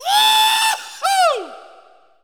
JAUCHZER   3.wav